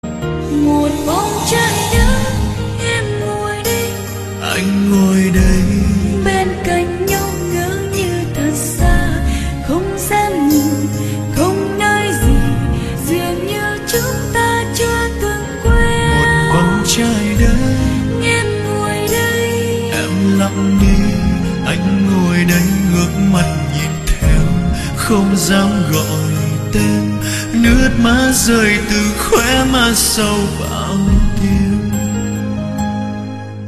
Thể loại Nhạc Trẻ | Chất lượng 320kbps.